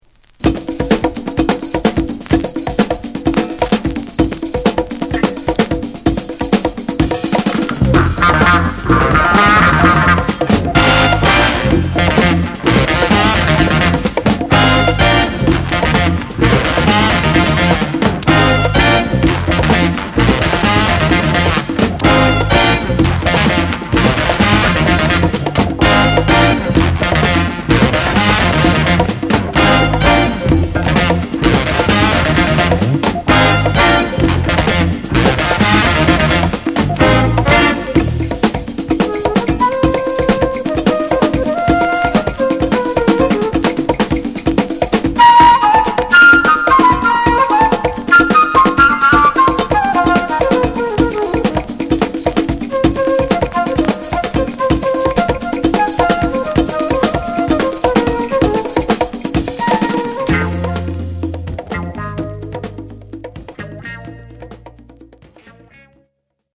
ファンク・クラシック45s！！
カップリングのパート2はブレイクから始まります。